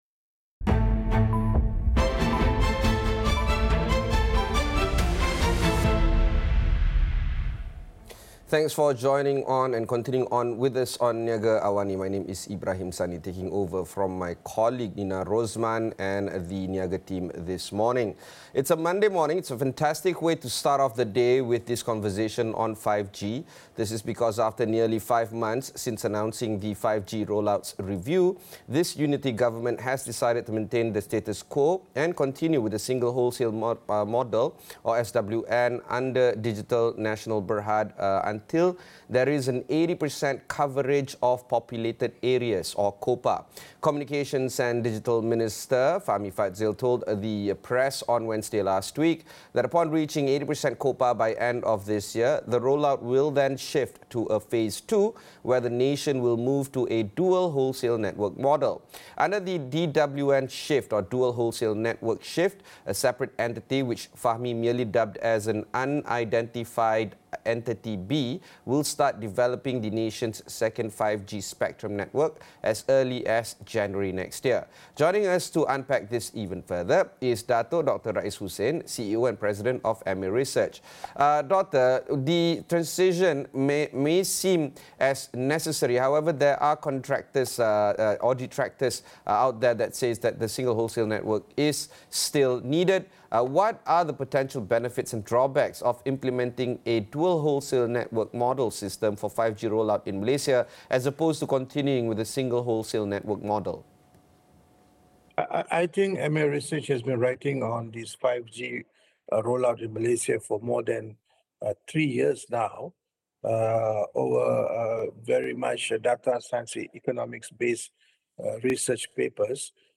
Discussion on whether Malaysian is prepared for the implementation of 5G and its potential impact on various sectors such as economy, education, healthcare, and entertainment